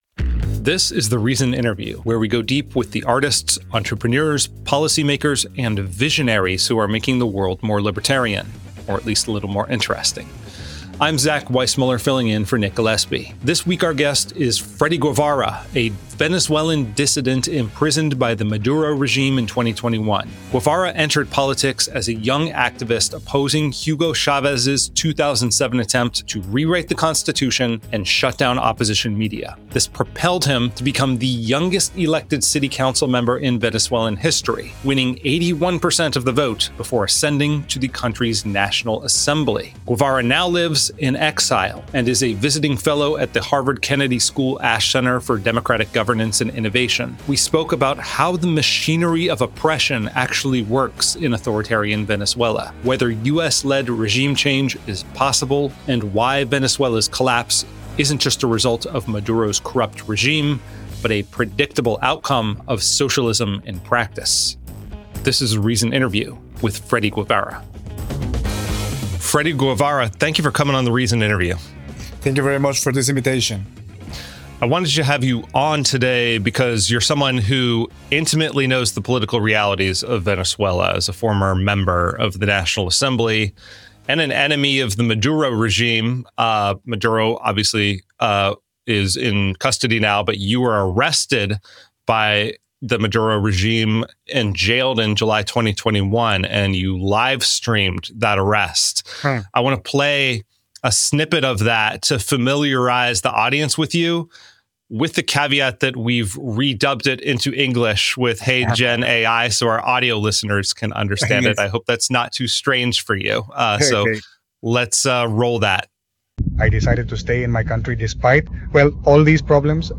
Venezuelan opposition leader Freddy Guevara explains support for U.S. intervention and how socialism destroyed Venezuela.